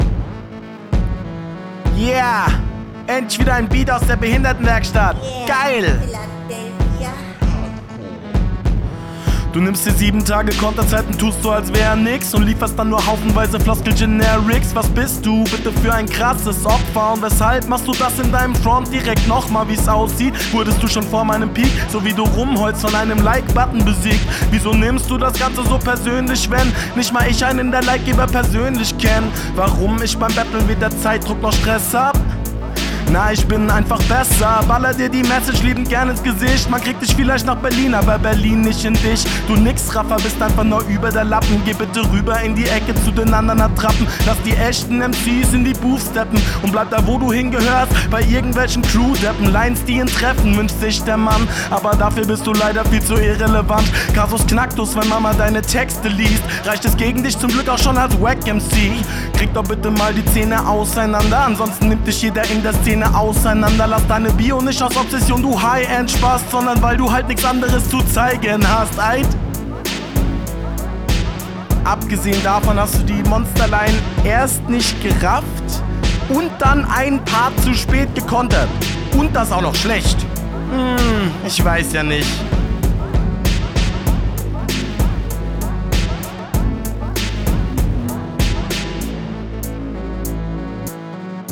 Sehr melodisch geflowt.